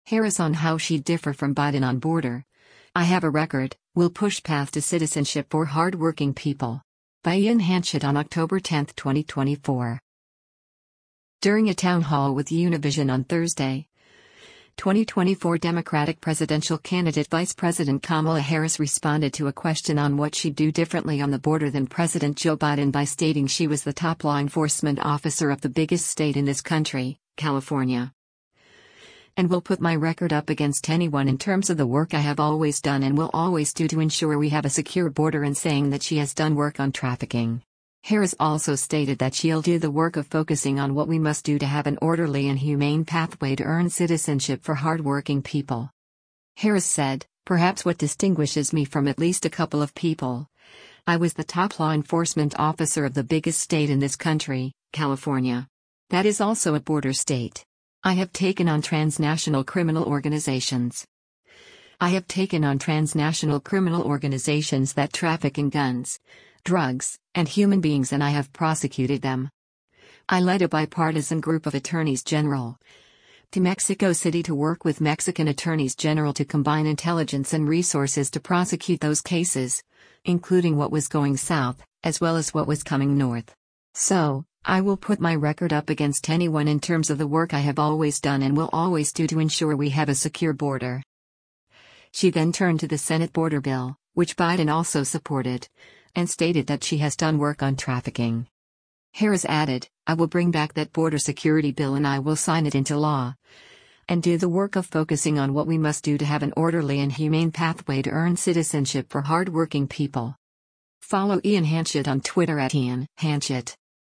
During a town hall with Univision on Thursday, 2024 Democratic presidential candidate Vice President Kamala Harris responded to a question on what she’d do differently on the border than President Joe Biden by stating she “was the top law enforcement officer of the biggest state in this country, California.”